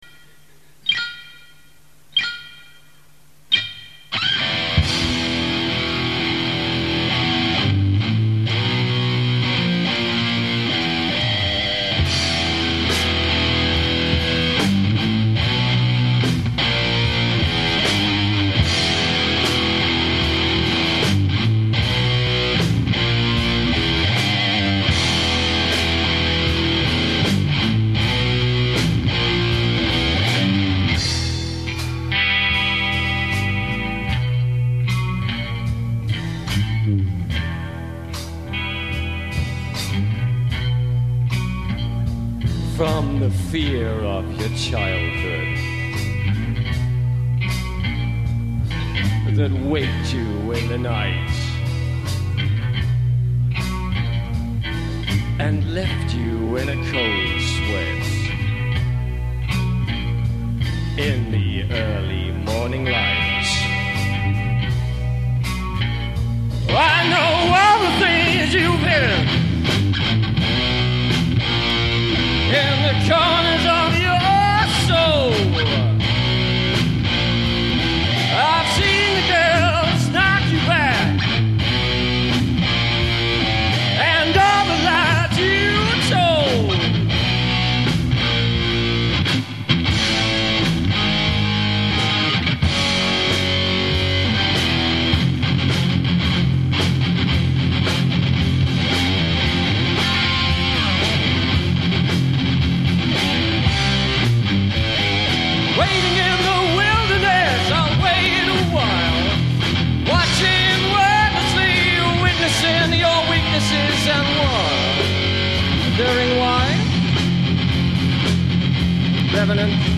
We weren't very good, but were are pretty loud :)
Here're some totally-not-mixed warts'n'all MP3s of the Dealers in concert:
Doom! Doom! And more Doom!
bass